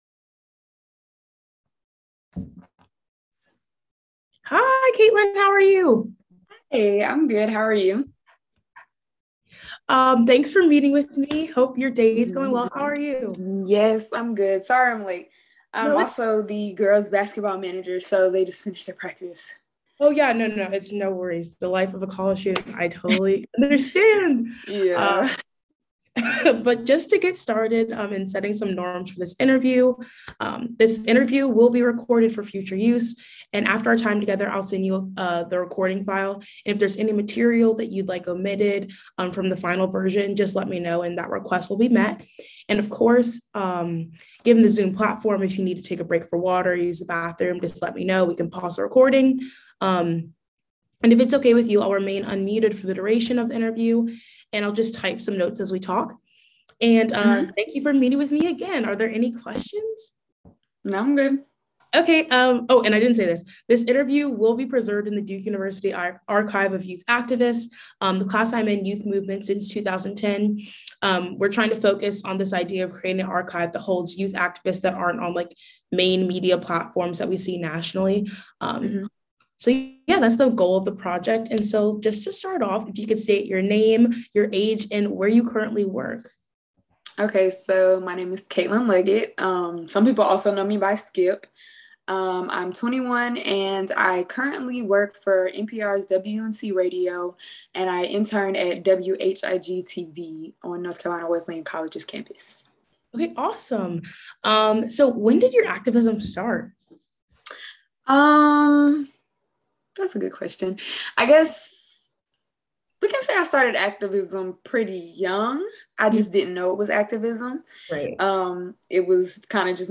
An interview
Conducted November 15, 2021 via Zoom.